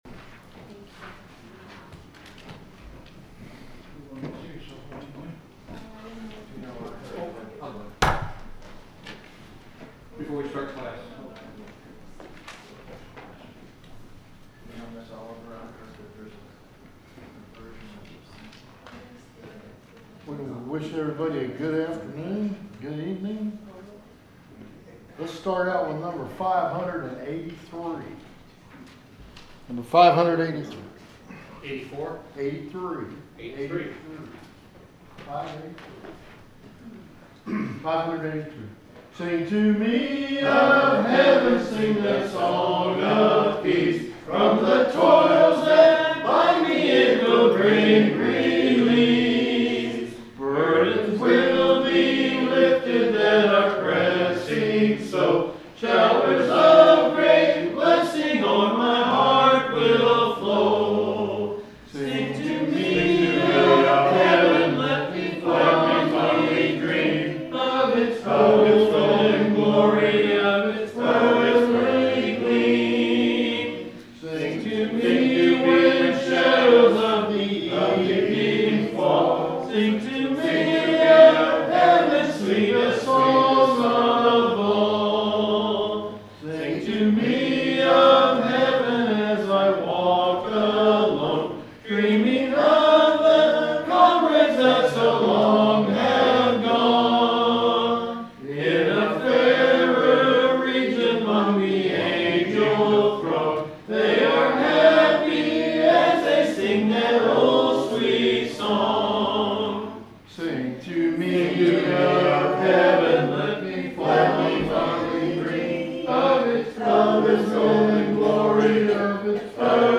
WEDNESDAY BIBLE STUDY
The sermon is from our live stream on 6/25/2025